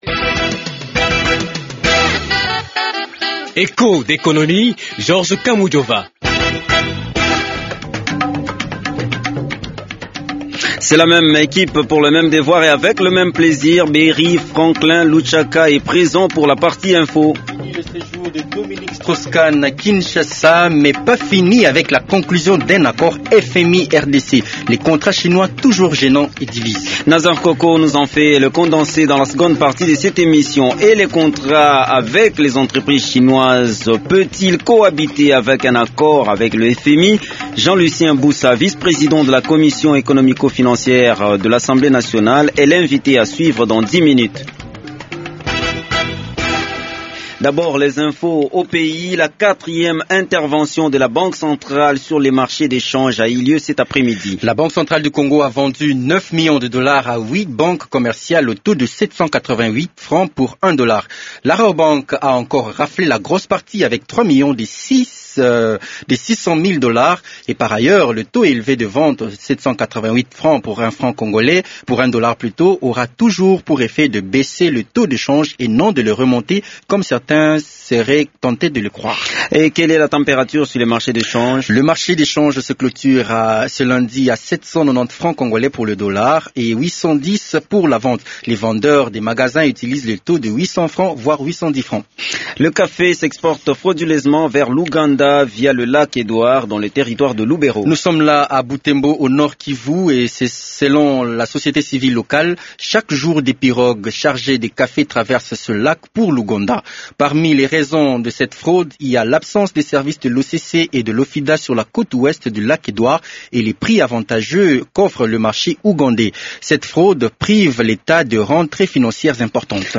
Echos d’économie reçoit aussi le député Jean Lucien Busa, vice-President de la commission économique et financière de l’assemblée nationale.